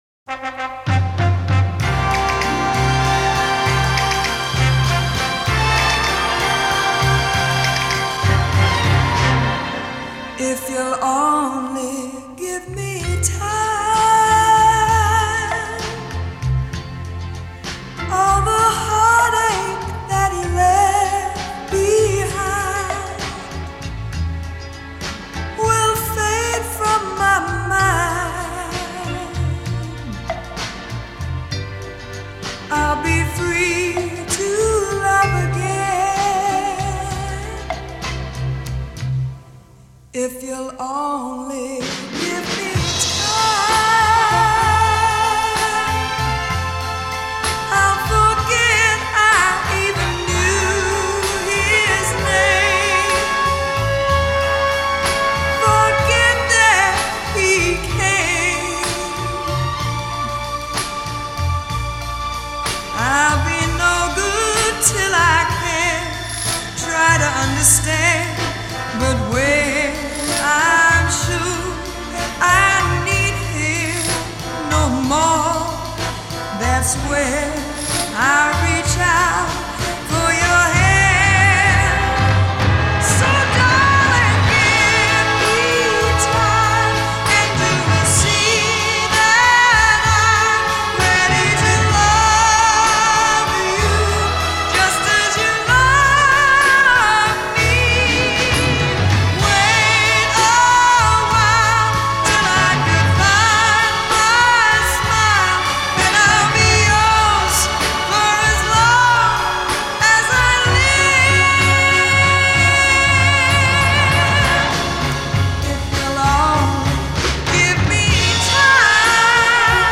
13 Over-the-Top Vocal Performances of The 1960s